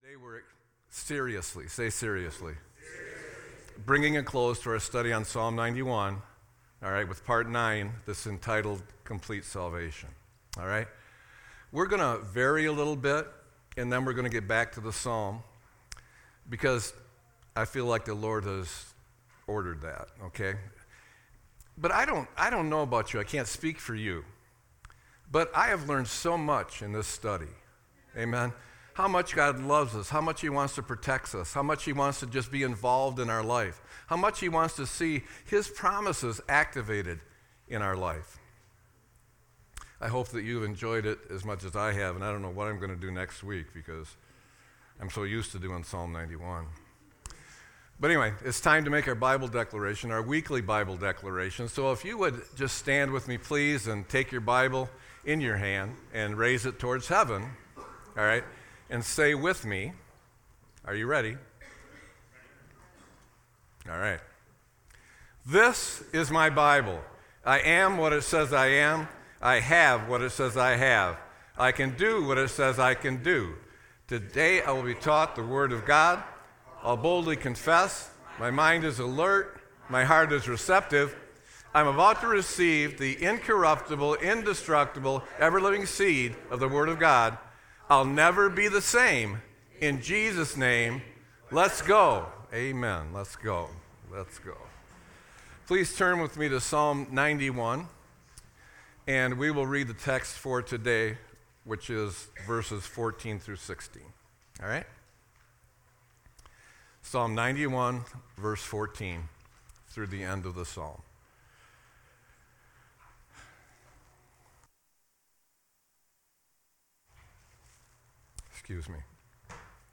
Sermon-3-09-25.mp3